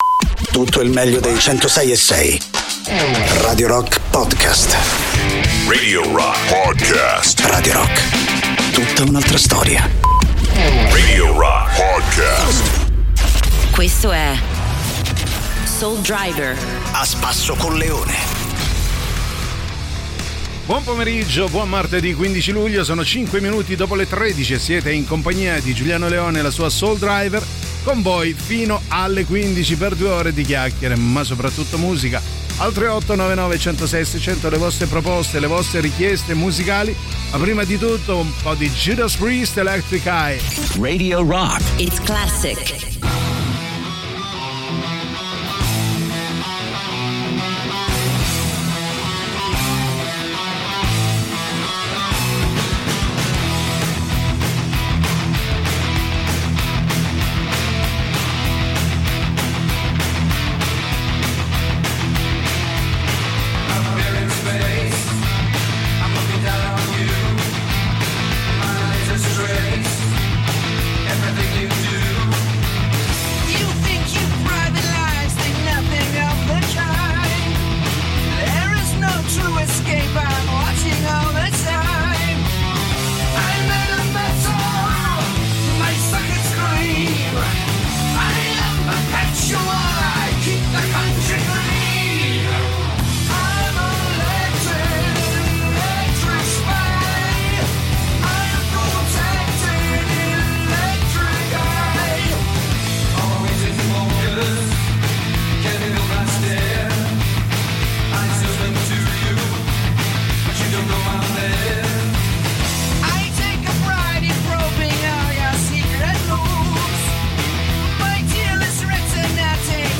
in diretta dal lunedì al venerdì, dalle 13 alle 15